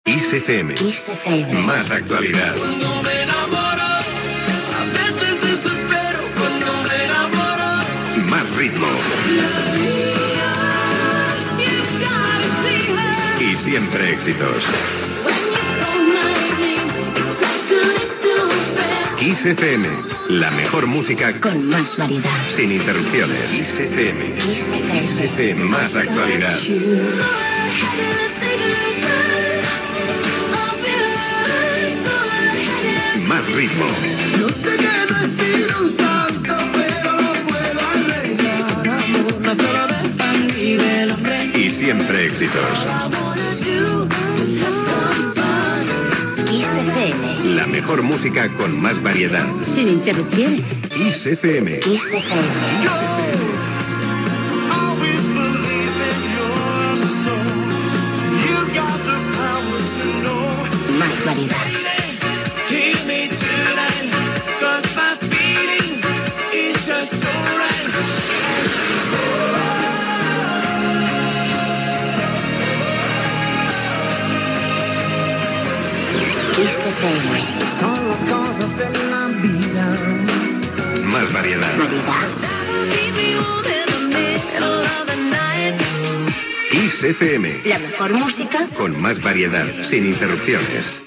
Indicatius